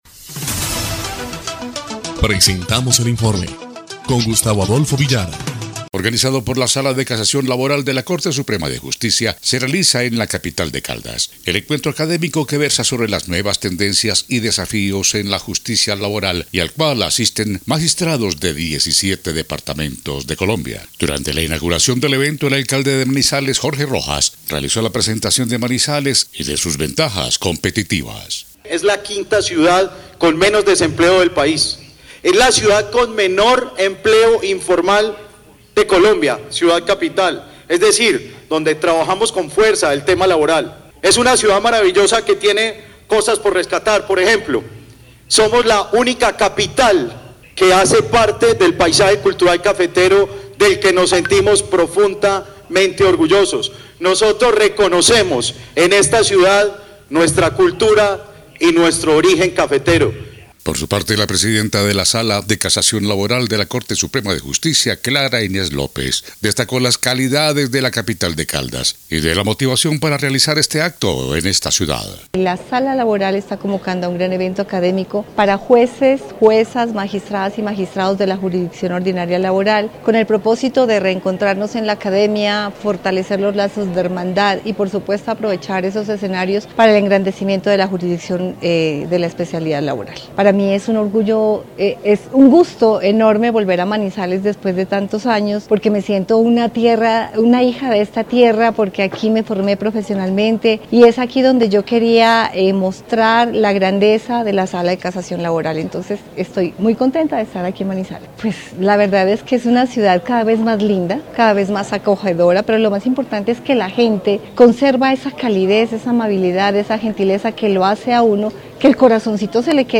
EL INFORME 3° Clip de Noticias del 10 de abril de 2025
-Jorge Eduardo Rojas. Alcalde de Manizales
-Clara Inés López. Presidenta Sala de Casación Laboral